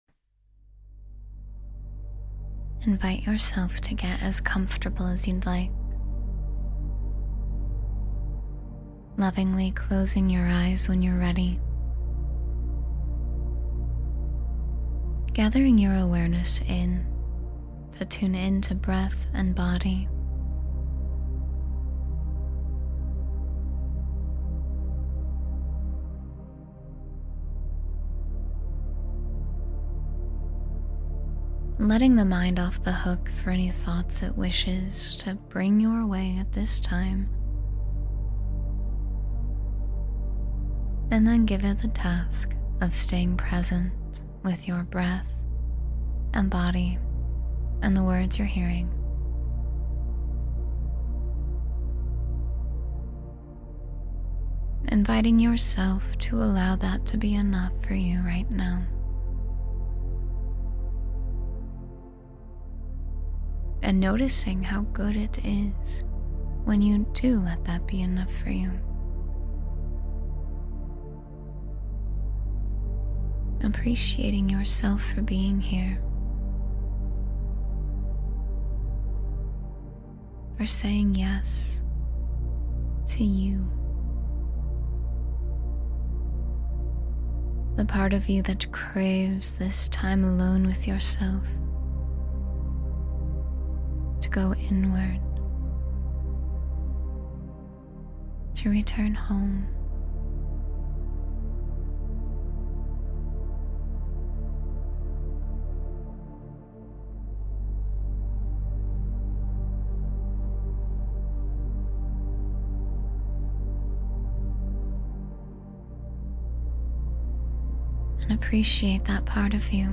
You Are Safe, You Are Okay — Meditation for Comfort and Reassurance